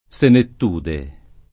senettute [Senett2te] (meno com. senettude [